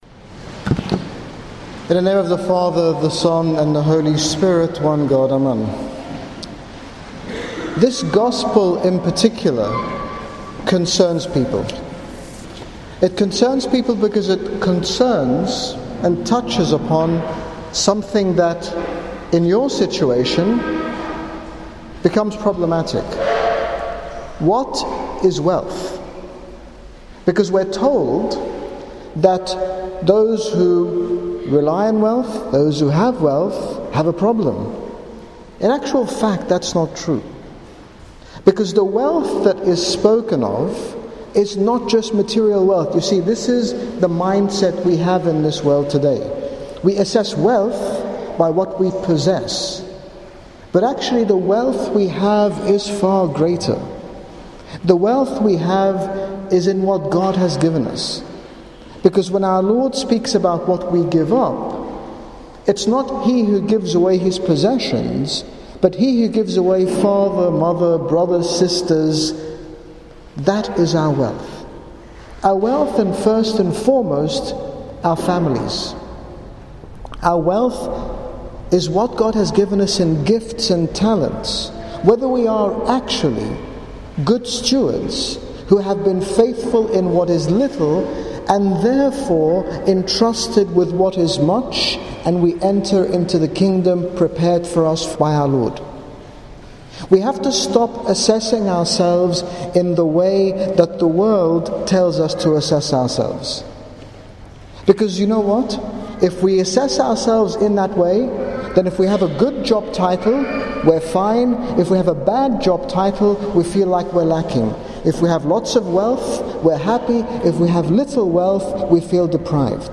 In this short sermon, His Grace Bishop Angaelos, General Bishop of the Coptic Orthodox Church in the United Kingdom, speaks about how we can measure our wealth or 'net worth' as being dependent solely on the image and likeness of God within us.